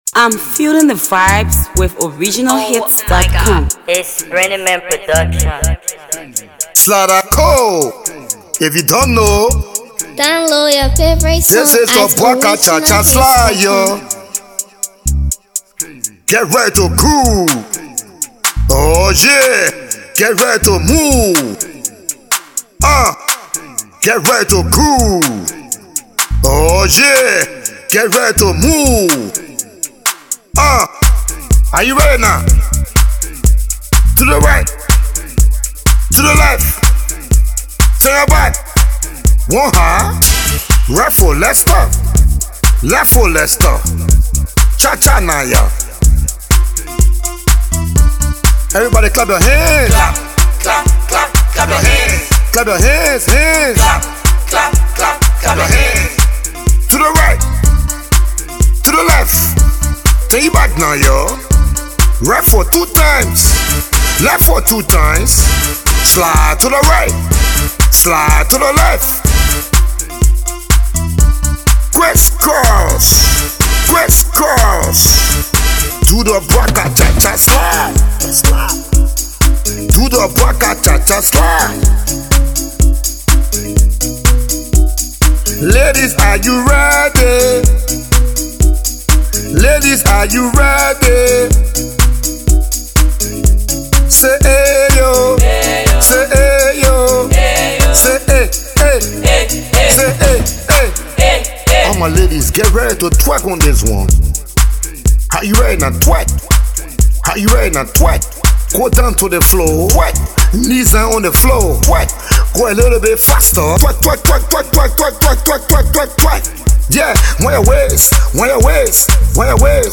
Hipco
studio effort